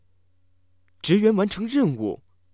surprise